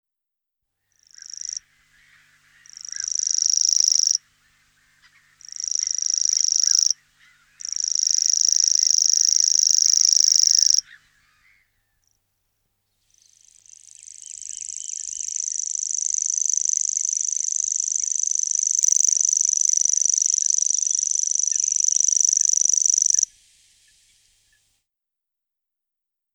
Орнитологи выявили 63 вида птиц, один из них, обыкновенный сверчок, занесен в региональную Красную книгу. Его песню, похожую на стрекотание кузнечика, слышали на пойменном лугу в окр. с. Малое Томышево Новоспасского района (
не забудьте и вы послушать необычный голос этой птицы).
sverchok_obyknovenniy.mp3